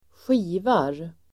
Uttal: [²sj'i:var]